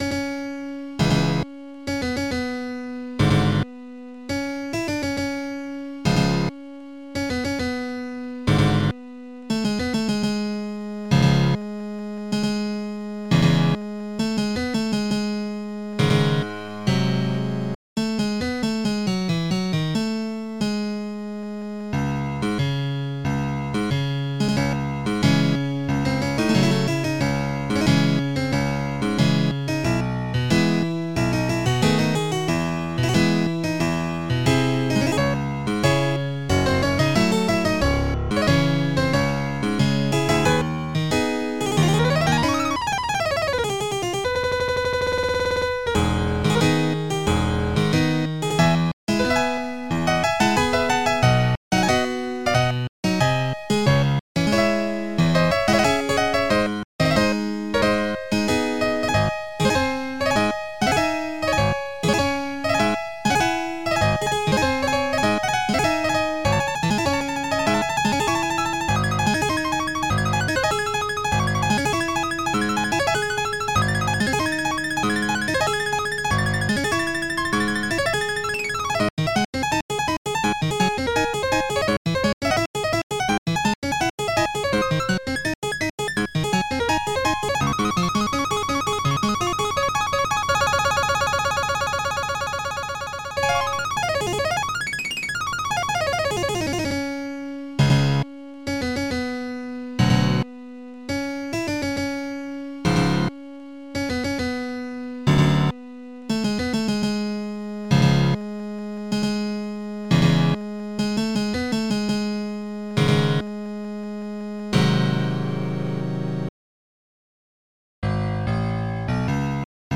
MED/OctaMED (4ch)
Piano2